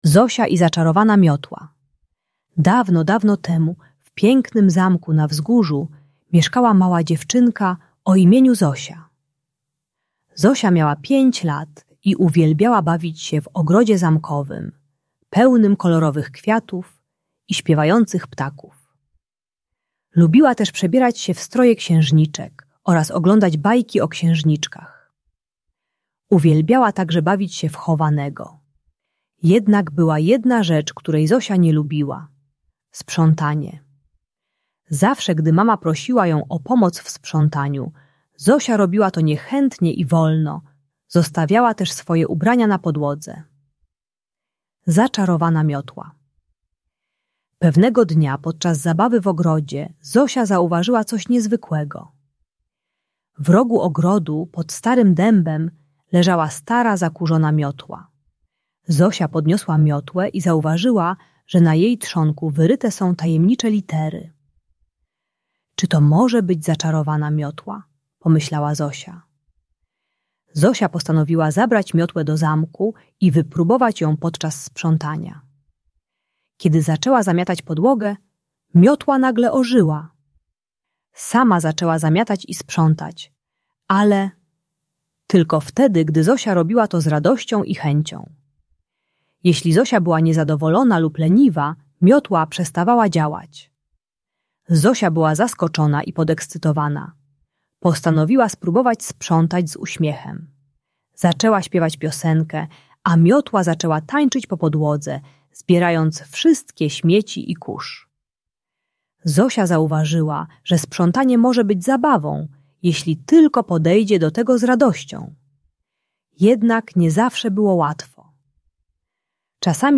Zosia i Zaczarowana Miotła - Niepokojące zachowania | Audiobajka